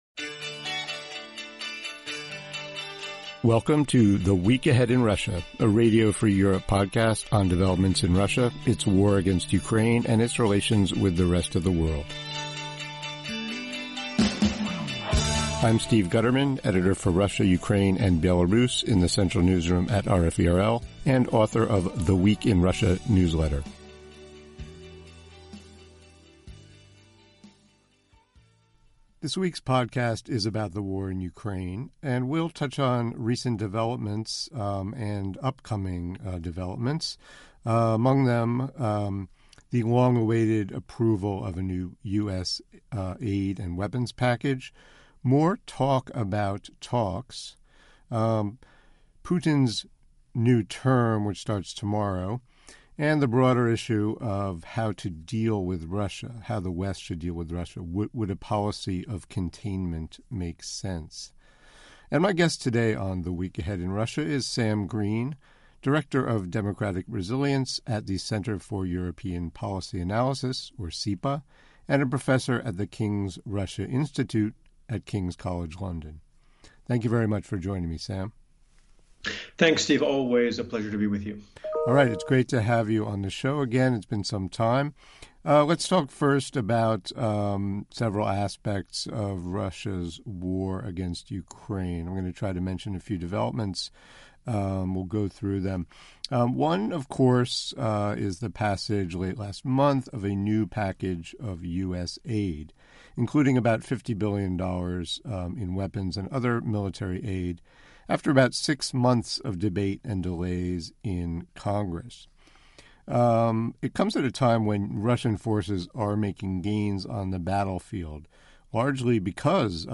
joins the host to discuss developments in Moscow's war on Ukraine and confrontation with the West.